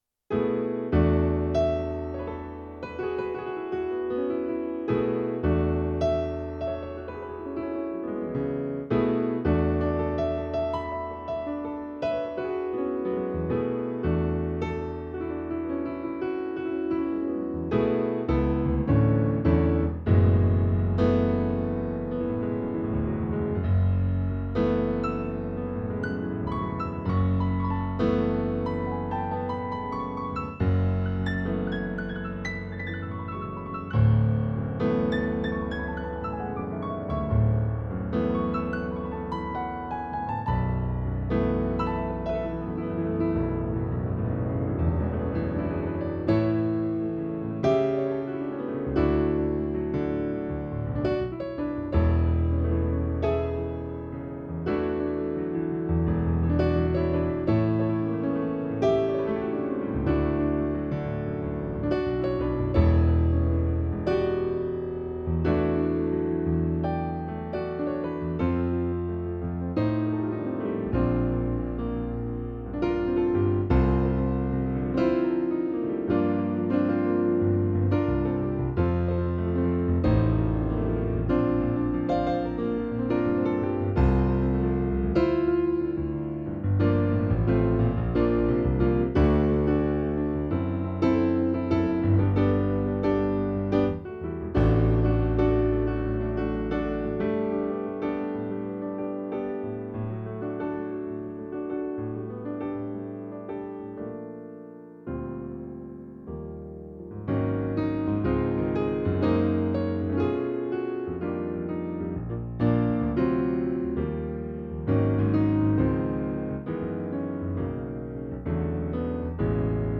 solo keyboard suite
Type: Home Recording Performers
piano) Location: Konstanz Source